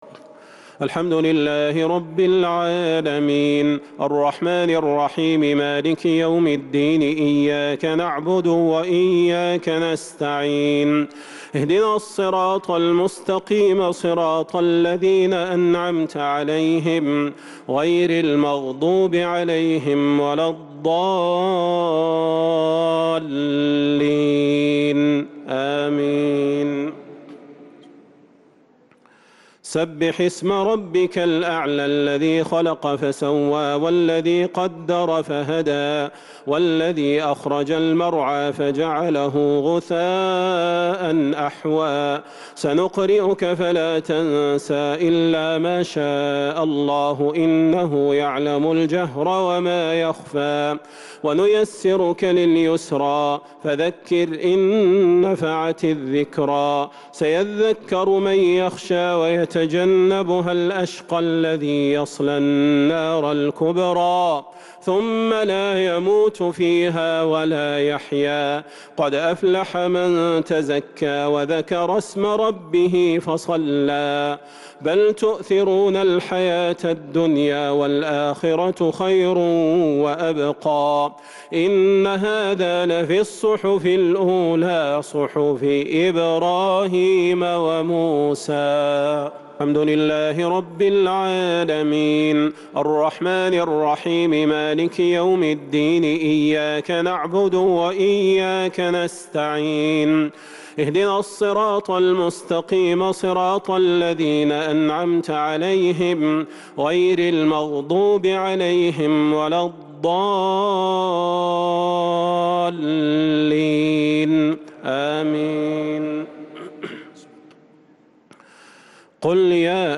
صلاة الشفع والوتر ليلة 1 رمضان 1443هـ Witr 1st night Ramadan 1443H > تراويح الحرم النبوي عام 1443 🕌 > التراويح - تلاوات الحرمين